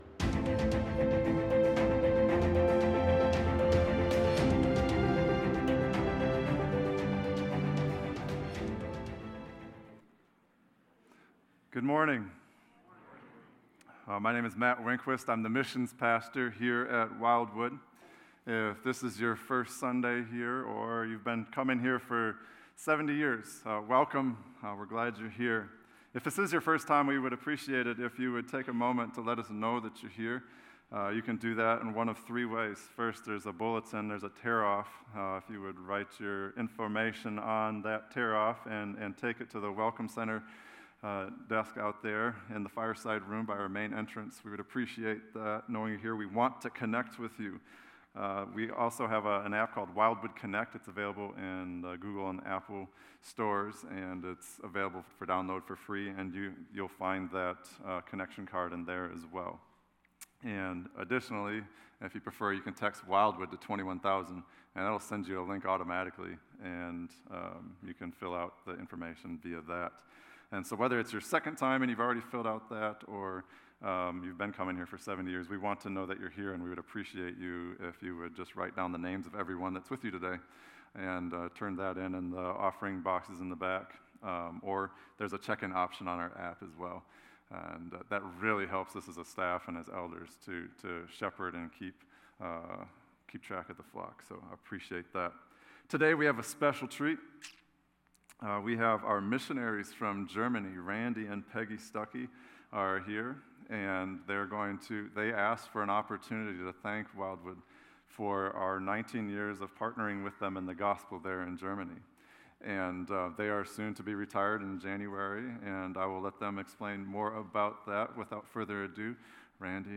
A message from the series "Wisdom From Above." The Word of God affects every aspect of our lives.